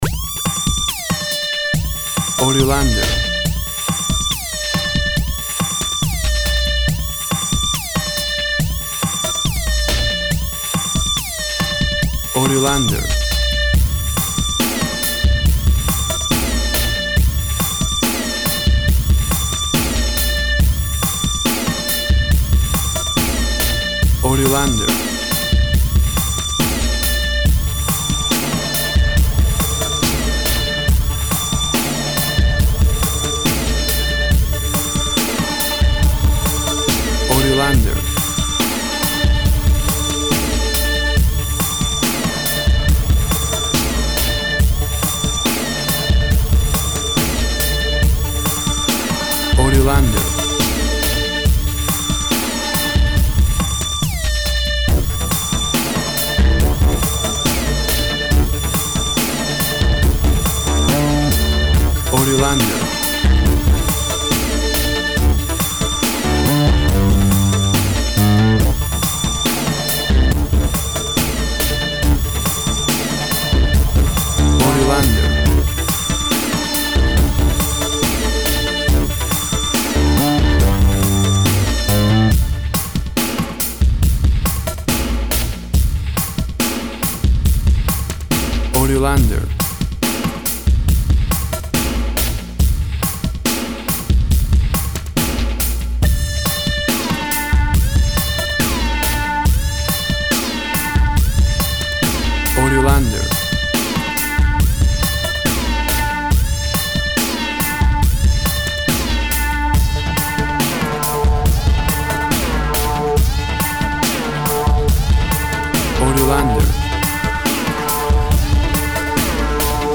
A high paced adrenaline fueled piece of action music
WAV Sample Rate 16-Bit Stereo, 44.1 kHz
Tempo (BPM) 70